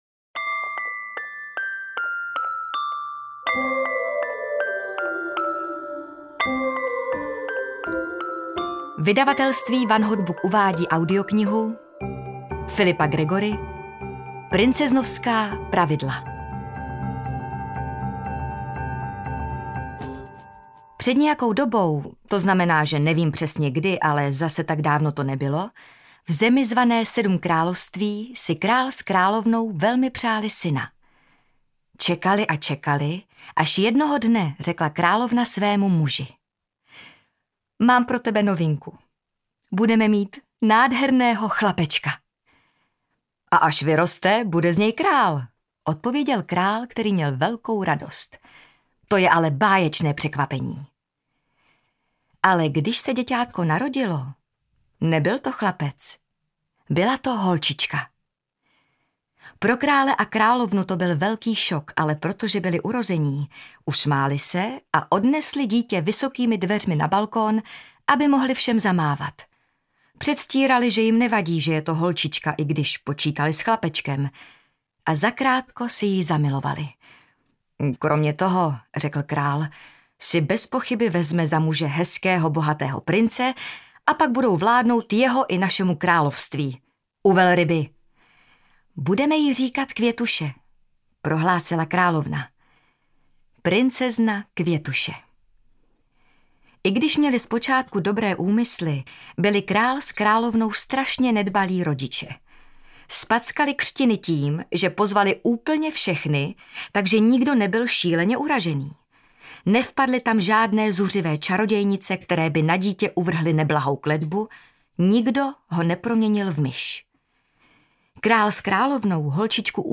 Princeznovská pravida 1 audiokniha
Ukázka z knihy
• InterpretJana Plodková